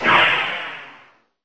missileaway.mp3